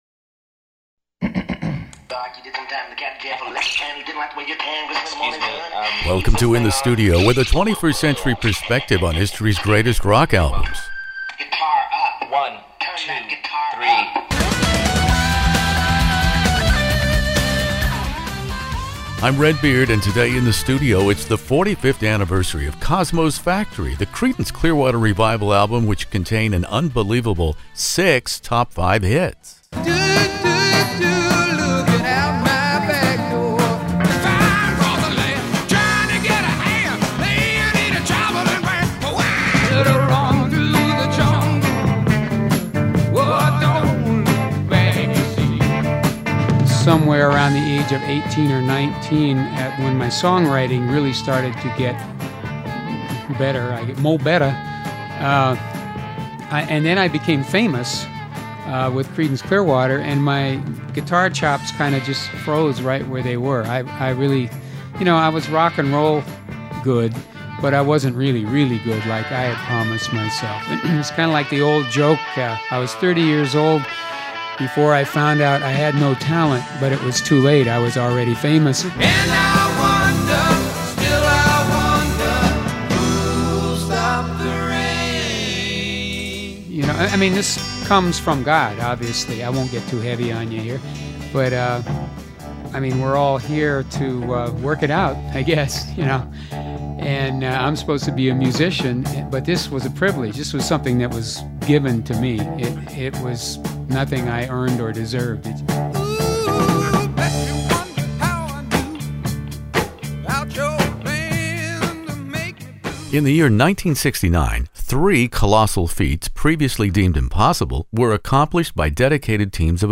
Enjoy my very rare classic rock interview.